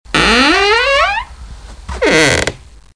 SFX开门声 01音效下载
SFX音效